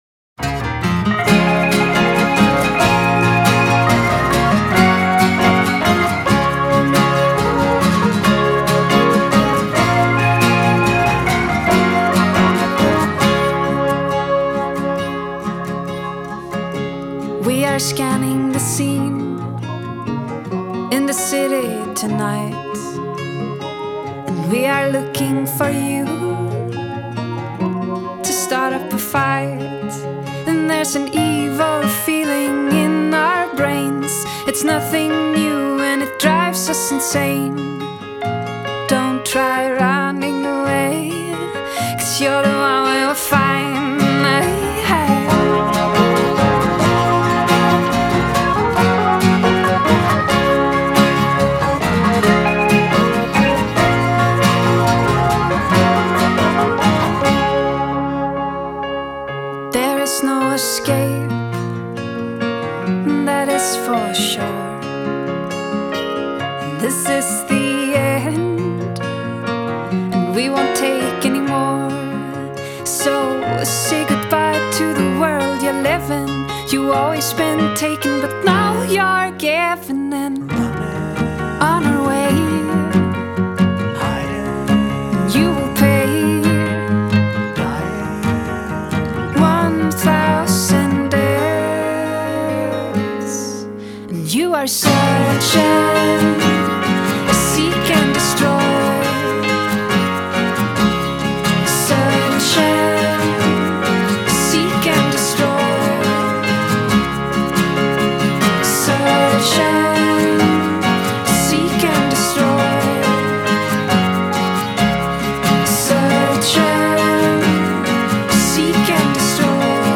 versiones pop de grandes éxitos del heavy metal